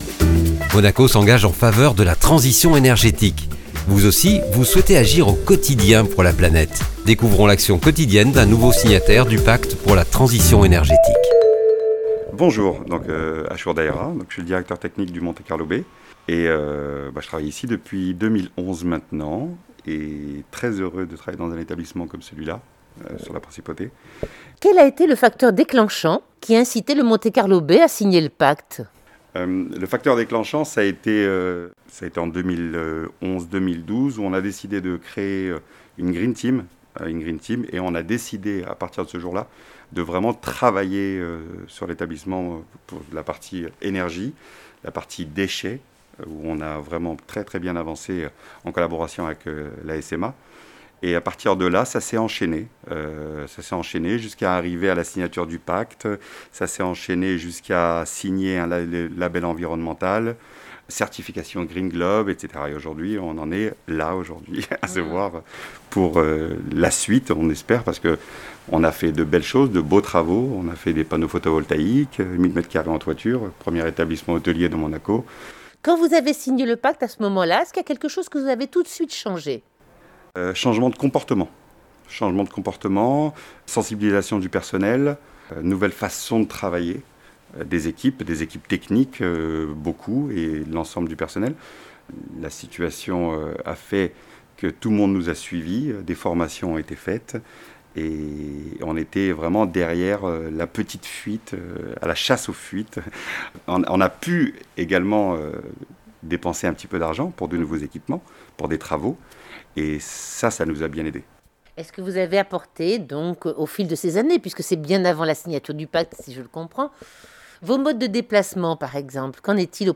interview_mte_2020_monte_carlo-bay.mp3